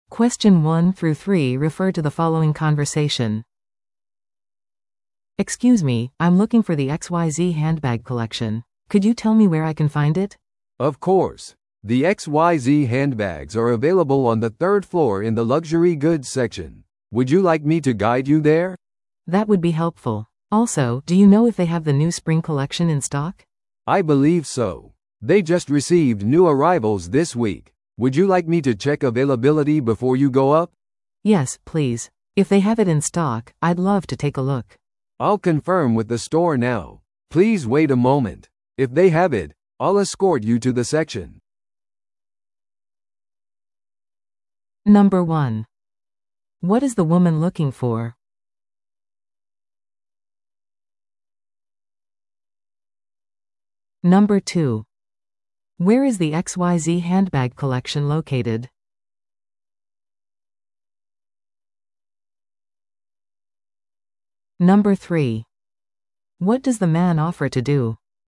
No.1. What is the woman looking for?
No.3. What does the man offer to do?